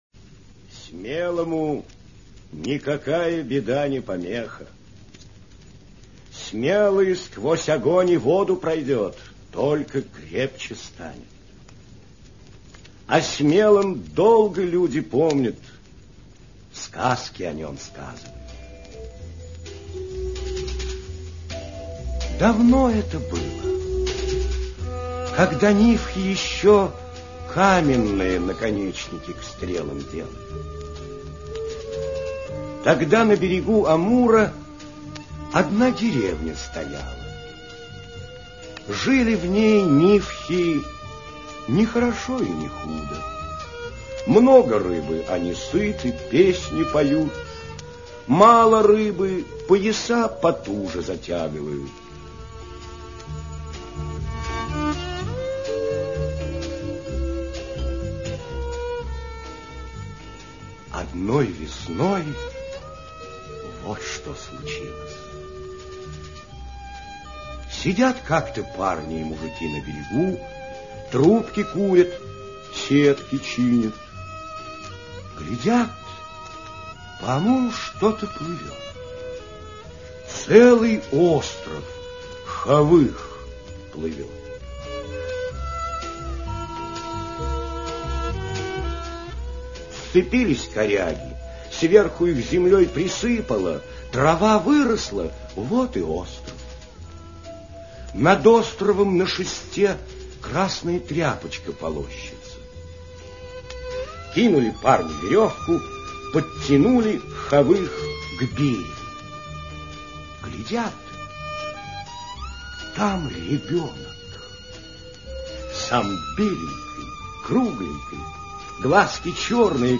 Храбрый Азмун - нивхская аудиосказка - слушать онлайн